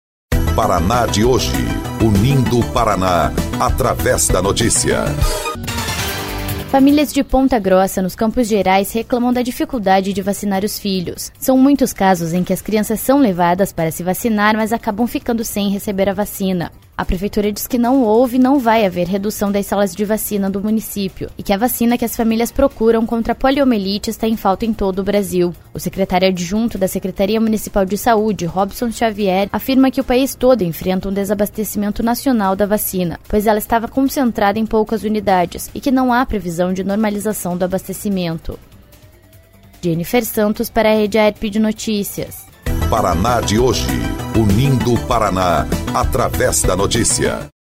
19.04 – BOLETIM – Famílias reclamam de falta de vacina para filhos em Ponta Grossa